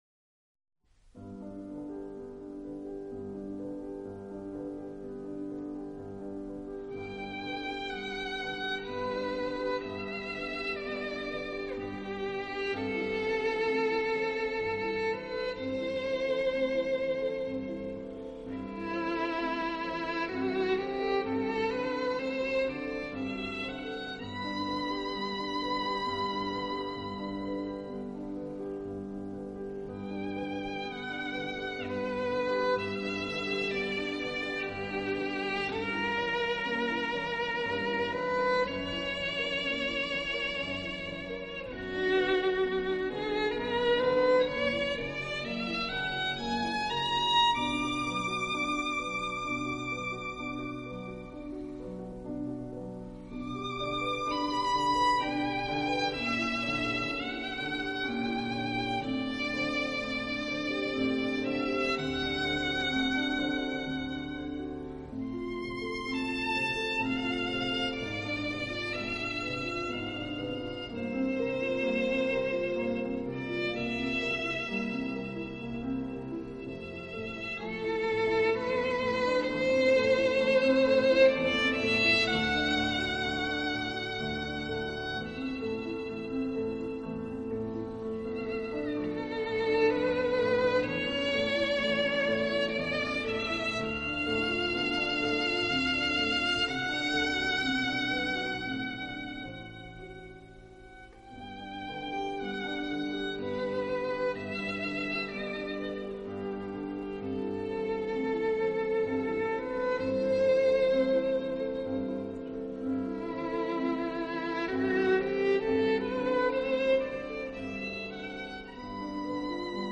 Acoustic（原音）是指原声乐器弹出的自然琴声（原音），制作录音绝对不含味精，乐器
本套CD音乐之音源采用当今世界DVD音源制作最高标准：96Khz/24Bit取样录制，其音源所
其音质与人声相似，富于歌唱性，